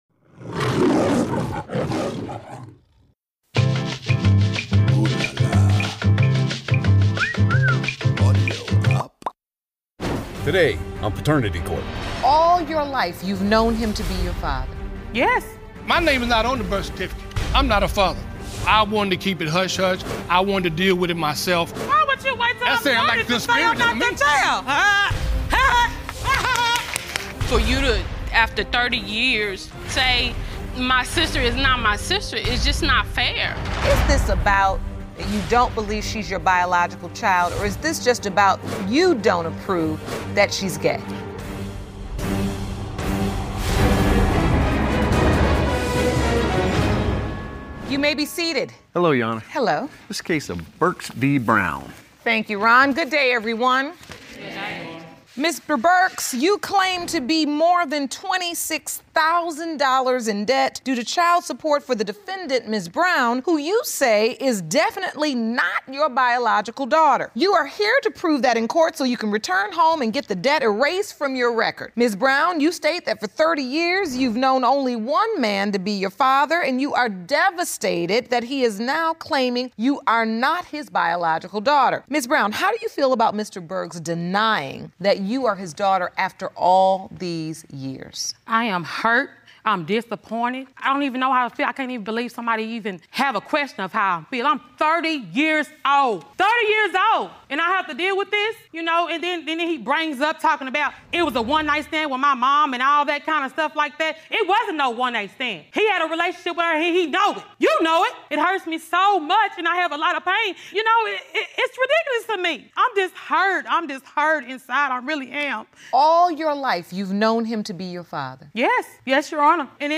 Daytime Emmy-award-winning court show, Lauren Lake’s Paternity Court - is now available as a podcast! Every episode, Judge Lauren Lake settles shocking paternity mysteries with unquestionable DNA results.